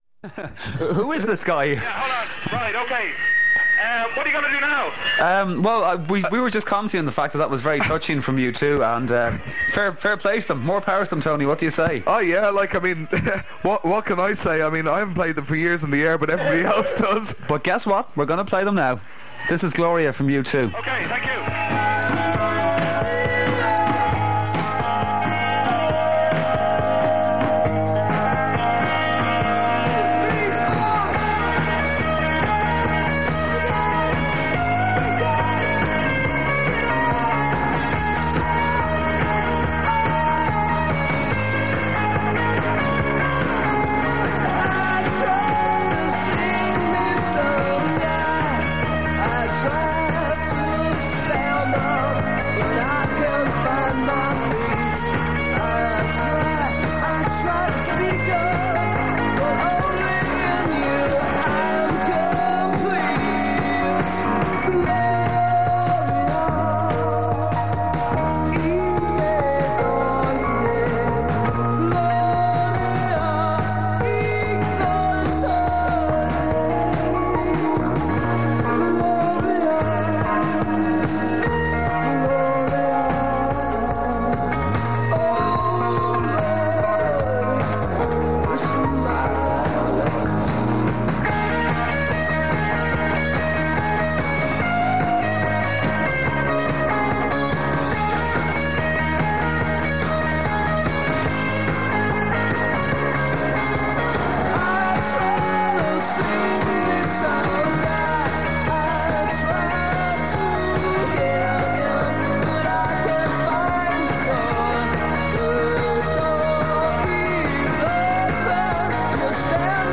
This recording is of the final half-hour of Capitol/Nitesky on New Year’s Eve 1988 as it prepared to close down for good.